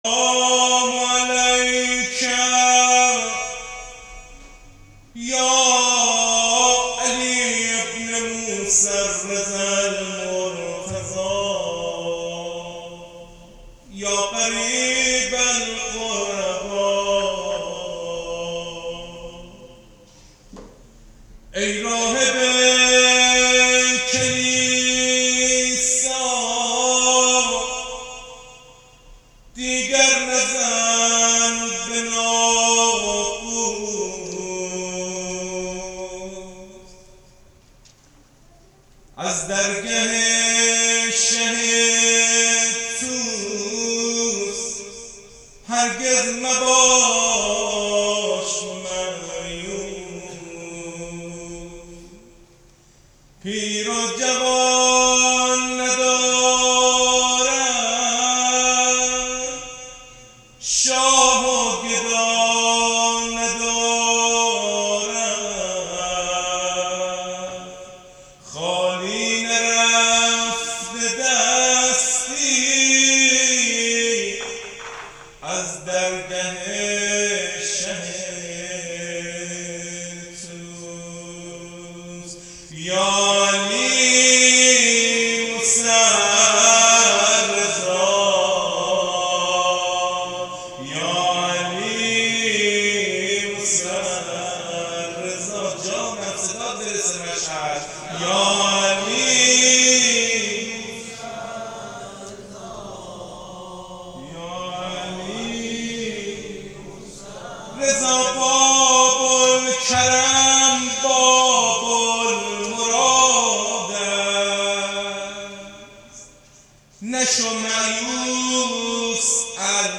زمزمه یا علی موسی الرضا